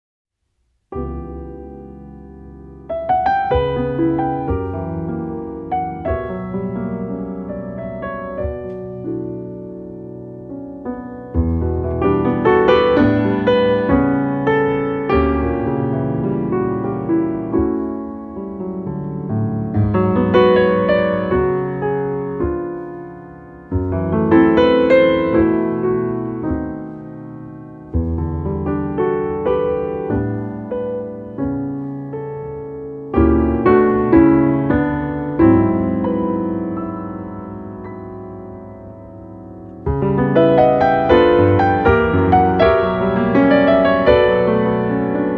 Compilation Jazz Album